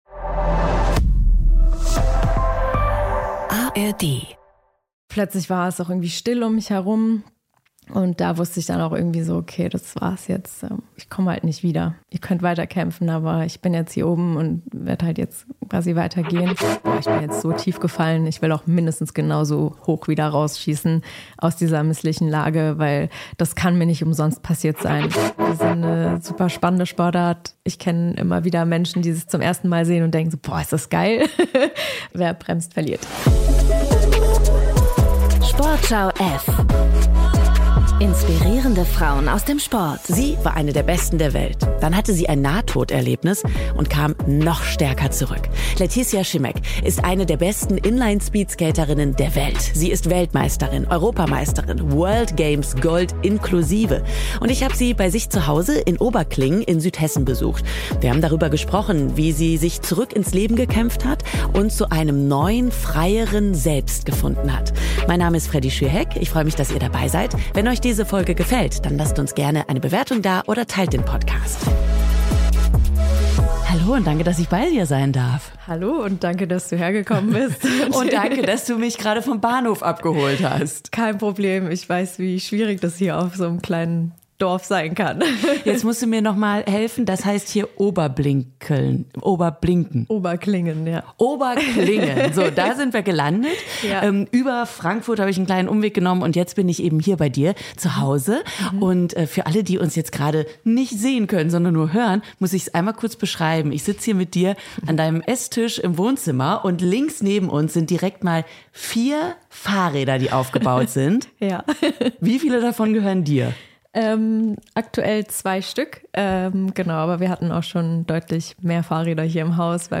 Ein Gespräch über Werte, den Umgang mit sich selbst und den Weg zu einem bedingungslosen Glück.